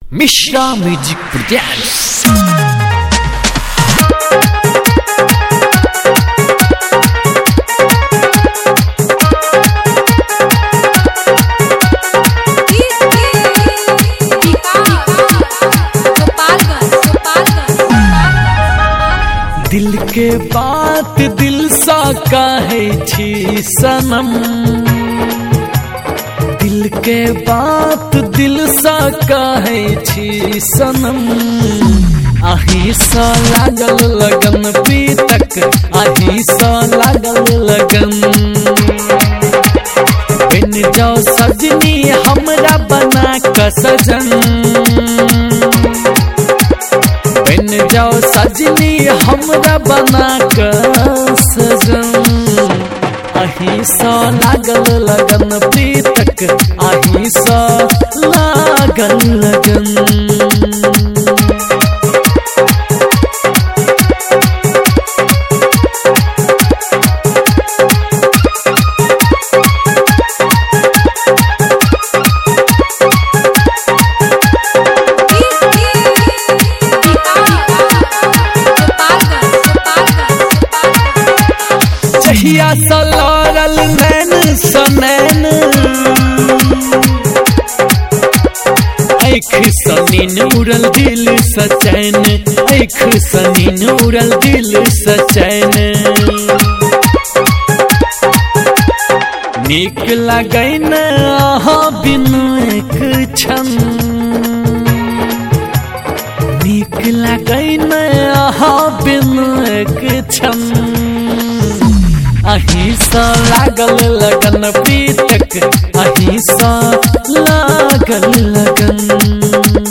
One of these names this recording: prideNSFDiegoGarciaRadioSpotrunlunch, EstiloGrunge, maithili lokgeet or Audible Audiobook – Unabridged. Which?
maithili lokgeet